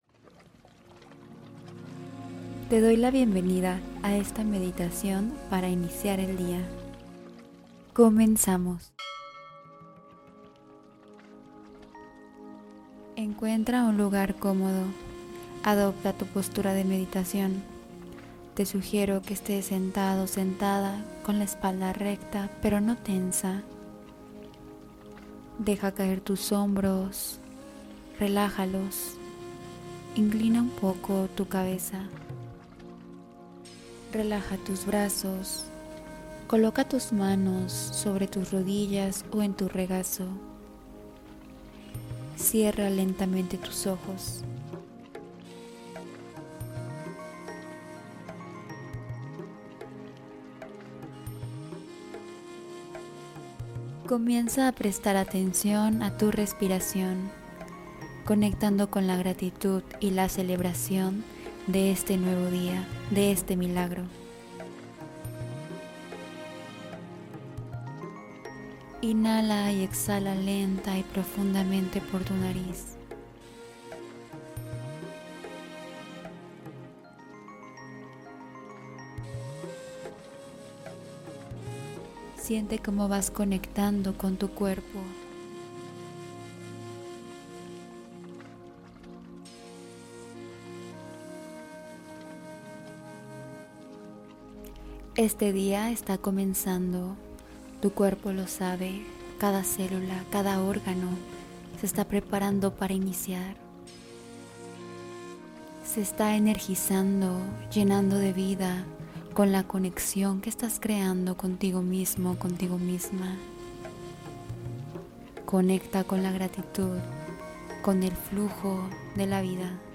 Suelta, Deja Ir y Descansa: Meditación para Liberar Emociones Profundas